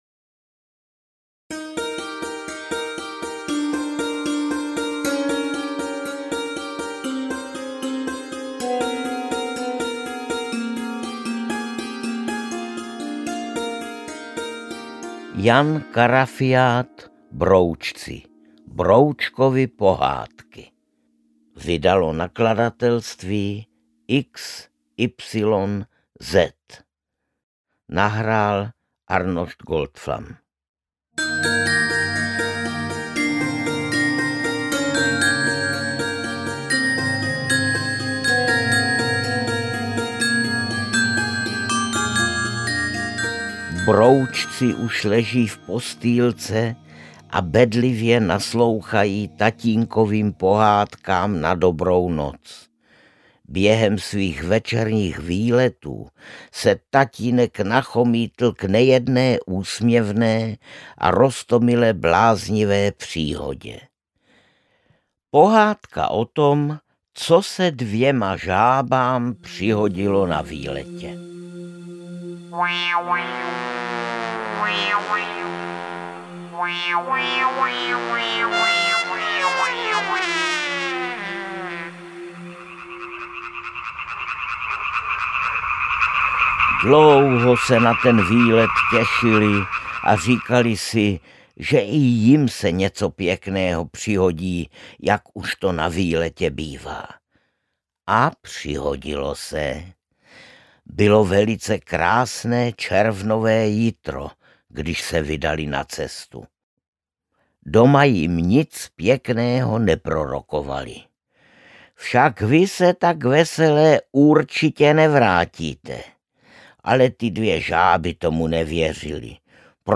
Interpret:  Arnošt Goldflam
AudioKniha ke stažení, 10 x mp3, délka 1 hod. 7 min., velikost 61,7 MB, česky